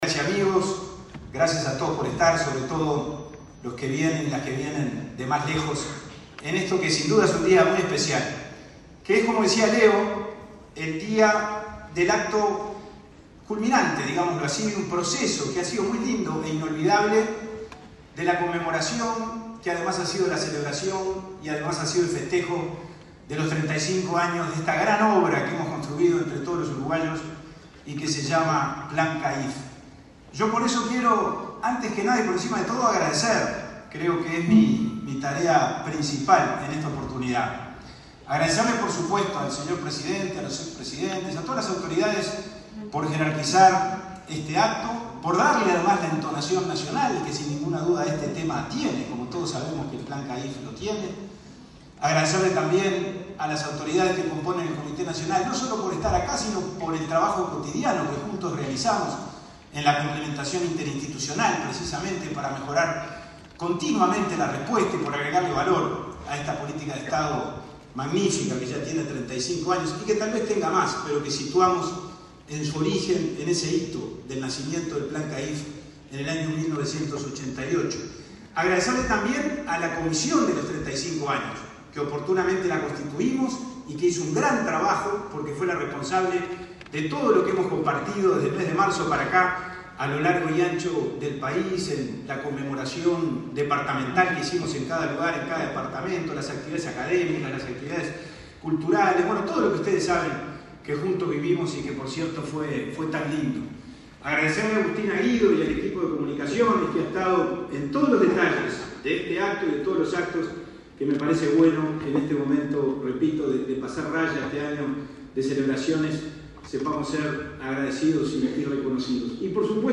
El presidente del Instituto del Niño y el Adolescente del Uruguay (INAU), Pablo Abdala, fue el orador principal en el acto de cierre de los festejos